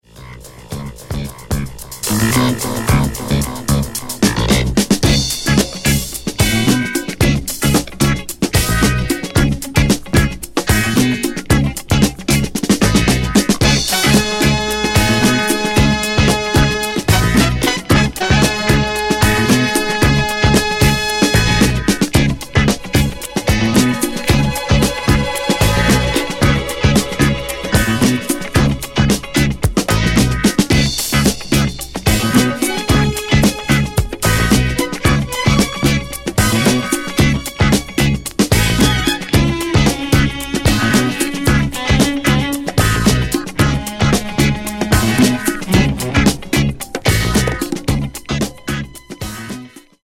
Genere:   Disco | Funk | Sunshine Sound